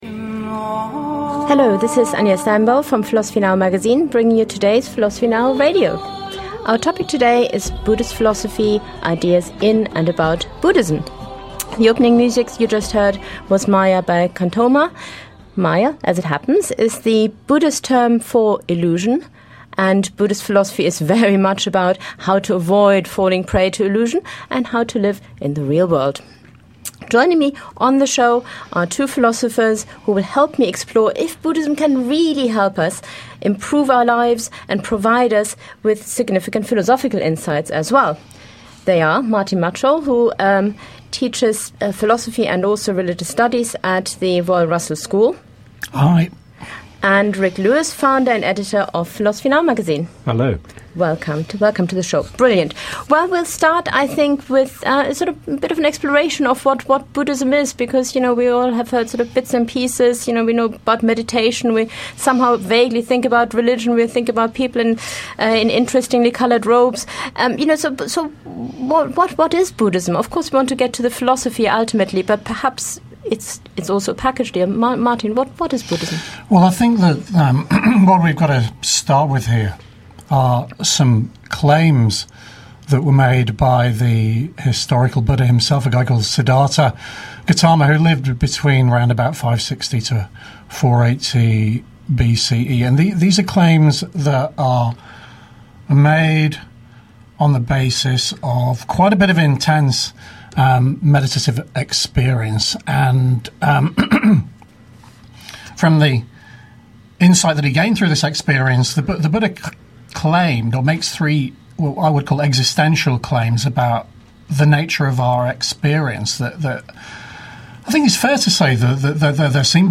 First broadcast on 18 May 2014 on Resonance FM.
Philosophy Now Radio Show Ep. 35 - Buddhist Philosophy.mp3